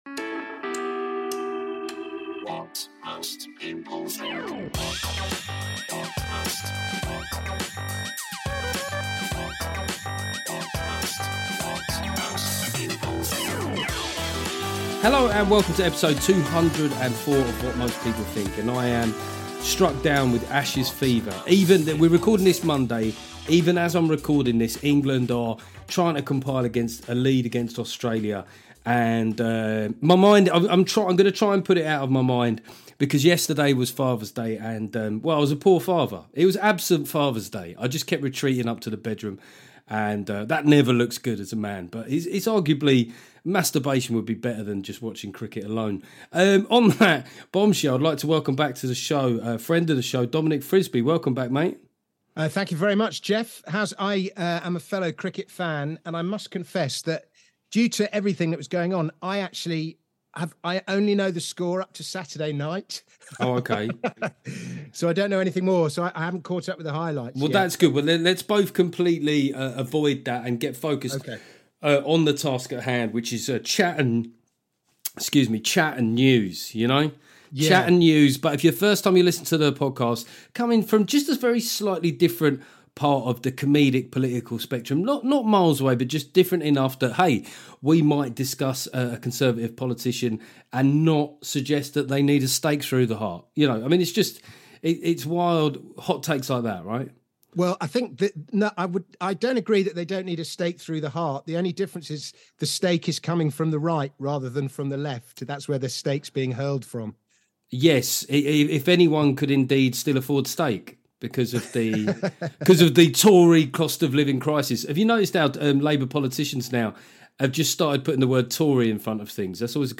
This week it's a lovely co-hosting episode with Dominic Frisby.